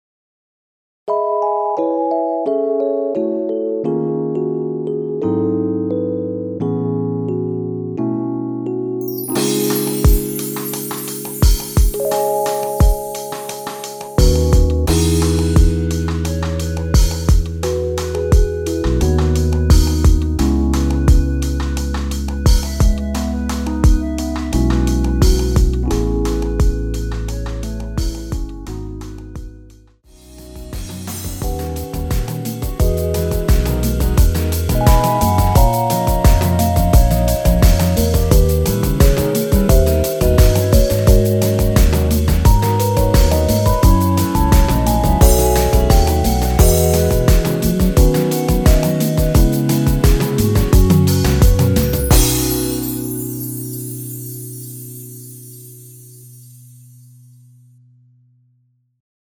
엔딩이 페이드 아웃이라 노래 끝나고 바로 끝나게 엔딩을 만들어 놓았습니다.(미리듣기 확인)
원키 멜로디 포함된 MR입니다.
앞부분30초, 뒷부분30초씩 편집해서 올려 드리고 있습니다.